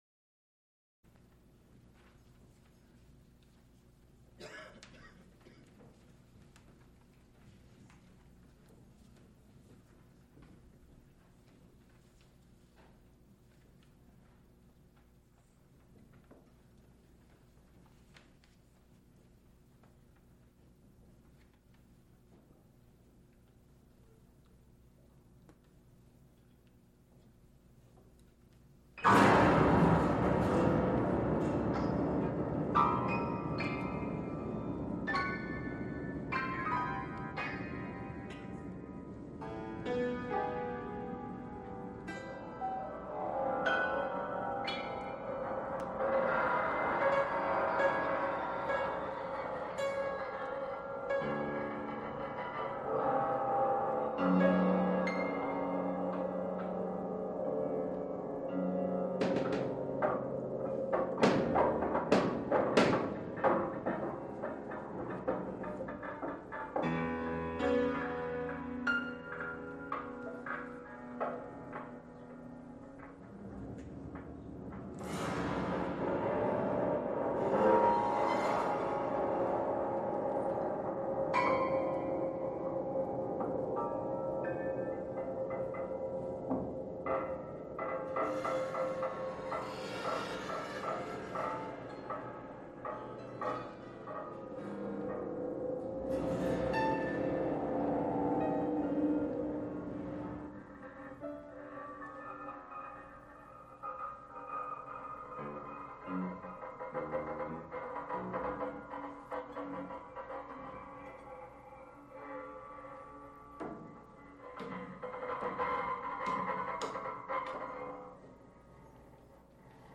Extent 1 audiotape reel : analog, half track, 7 1/2 ips ; 12 in.
sound recording-musical
musical performances
Music--20th century